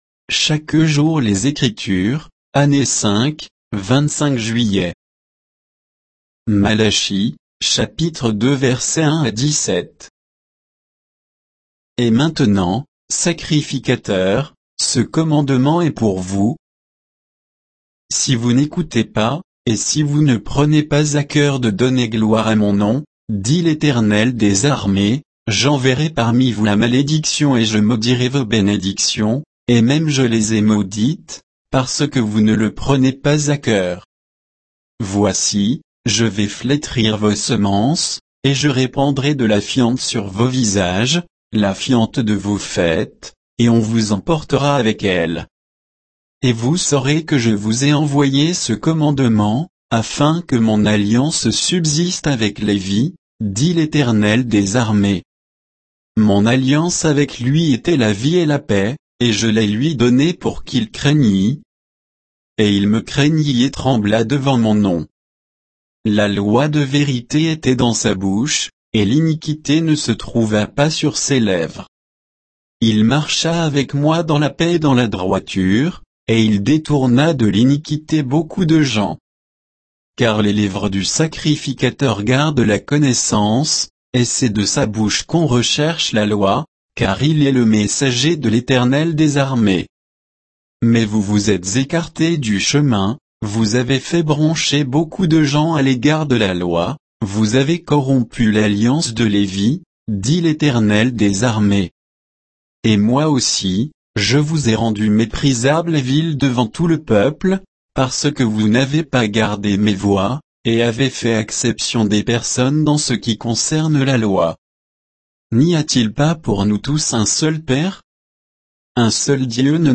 Méditation quoditienne de Chaque jour les Écritures sur Malachie 2